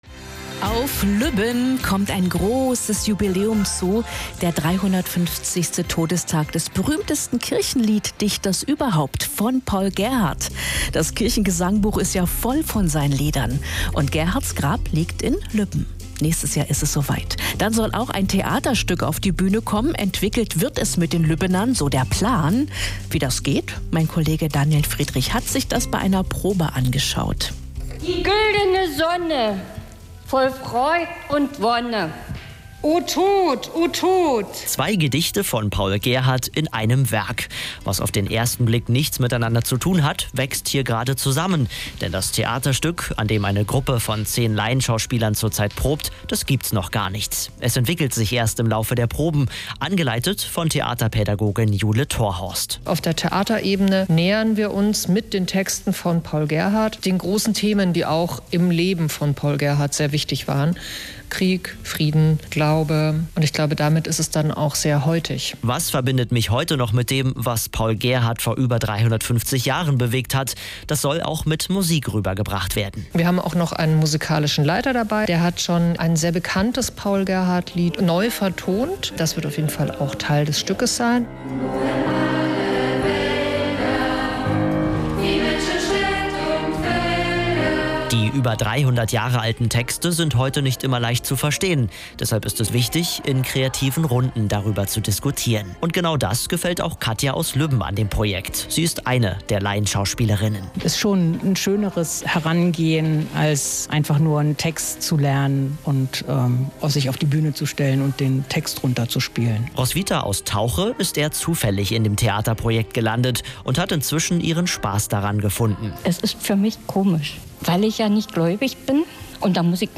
RADIOBEITRAG über die Proben (Klick aufs Bild):